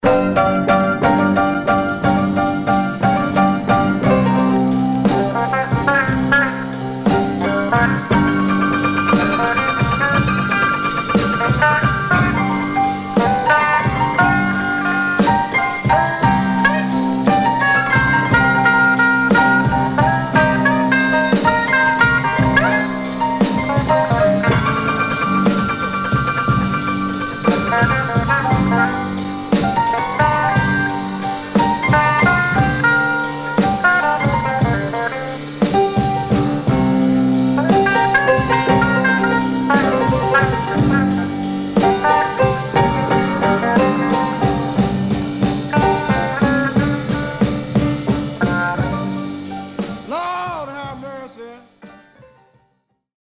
Mono
R&B Instrumental